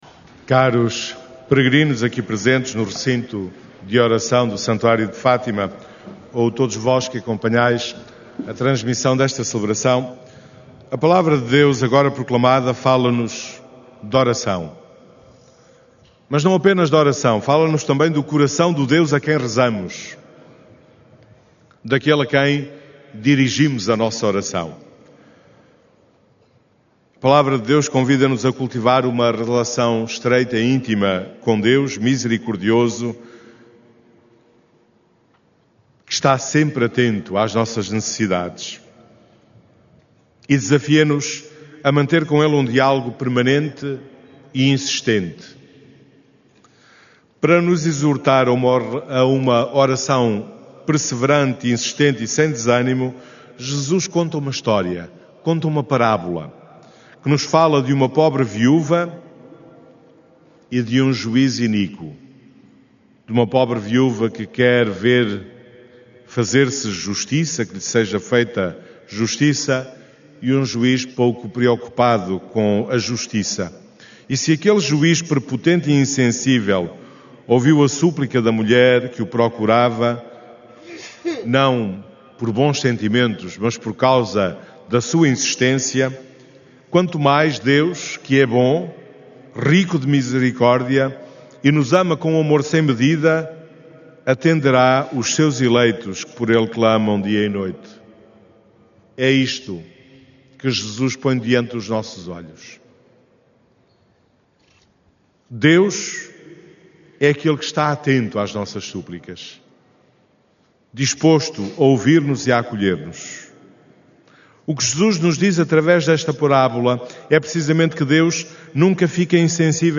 Áudio da homilia